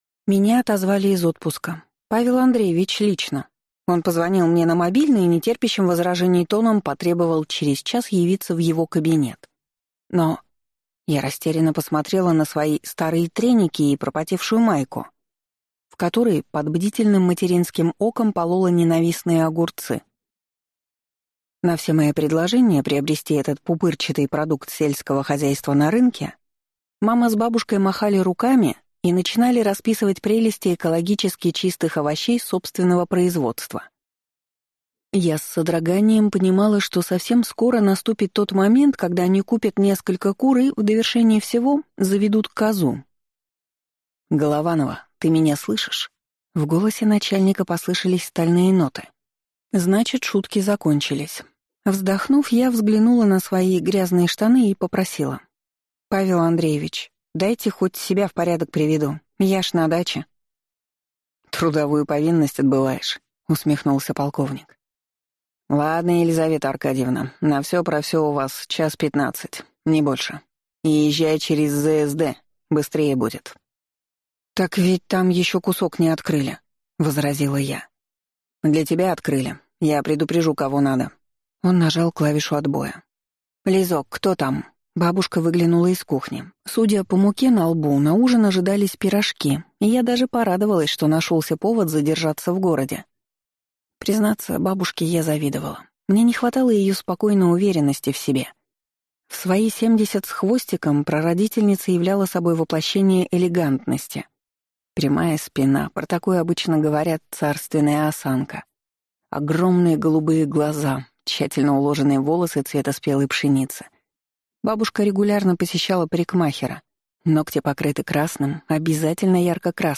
Аудиокнига Под грифом «Секретно». Книга 1. Принцесса по приказу | Библиотека аудиокниг